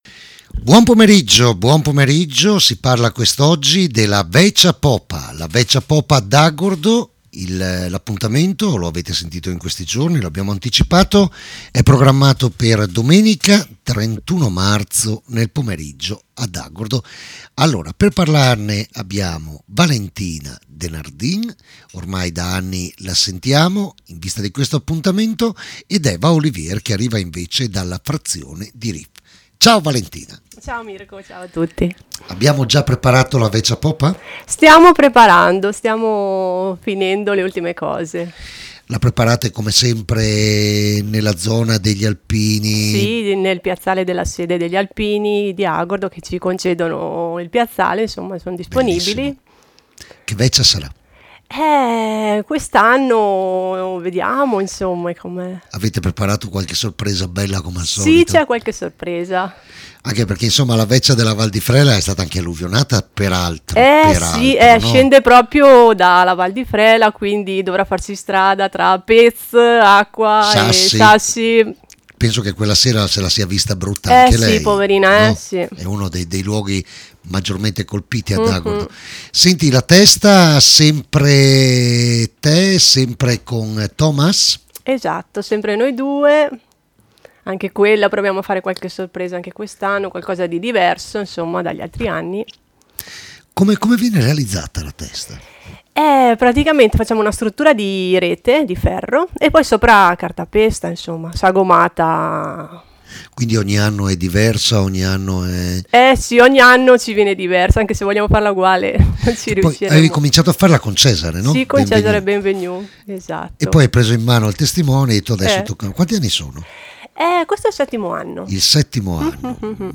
DALLO STUDIO 2 DI RADIO PIU, SPAZIO POMERIDIANO “CARPE DIEM”